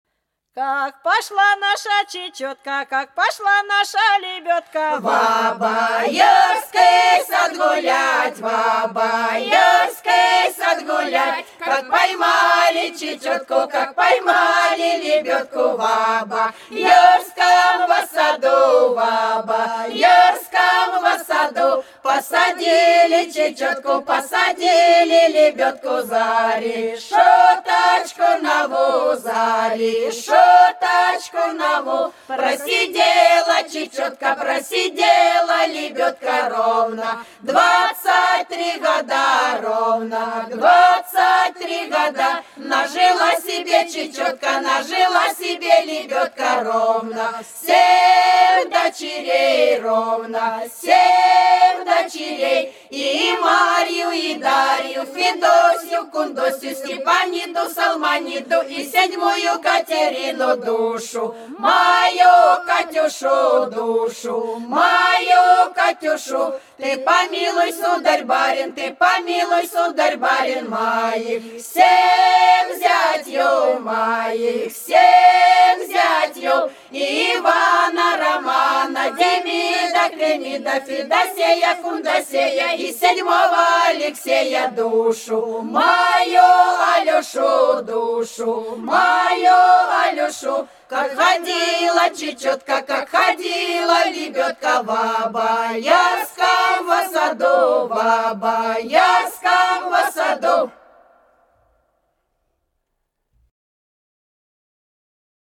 19_Как_пошла_наша_чечётка_-_плясовая.mp3